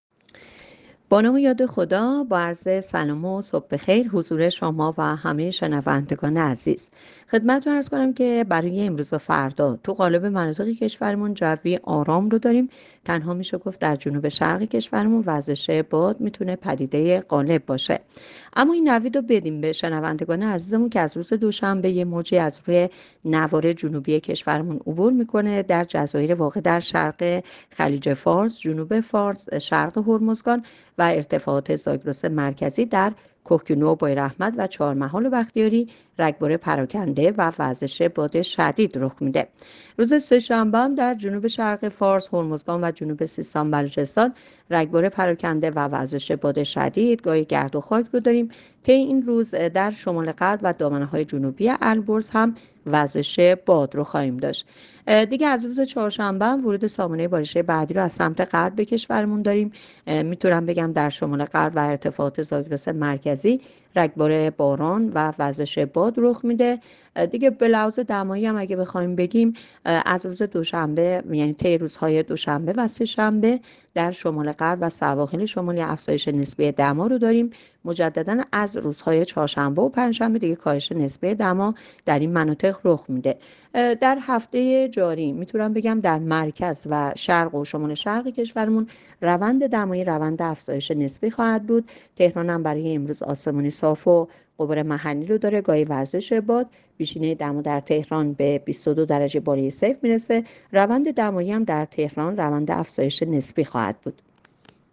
گزارش رادیو اینترنتی پایگاه‌ خبری از آخرین وضعیت آب‌وهوای ۲۵ اسفند؛